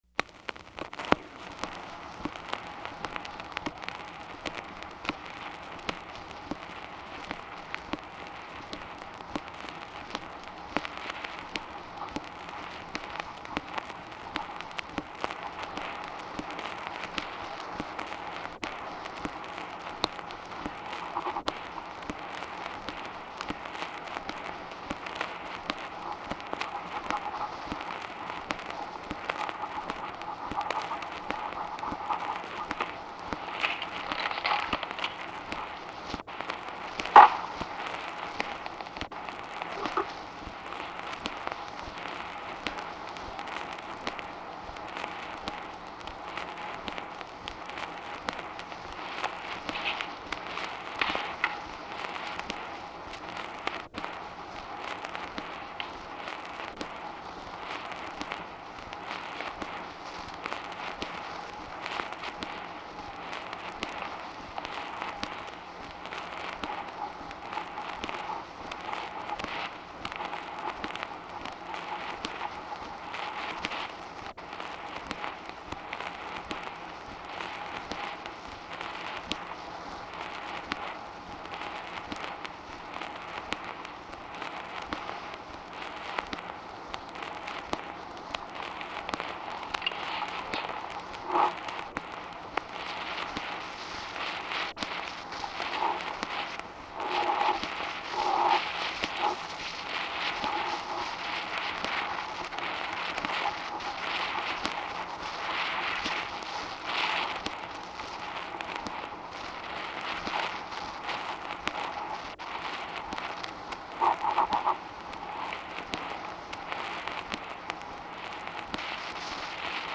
The first ten minutes of this recording are without conversation. Background noises such as papers shuffling, someone writing, a chair squeaking are the only distinguishable sounds. Near the end of the recording Eisenhower is heard speaking to a staff member. Their conversation is very brief and the staff member's comments are unintelligible.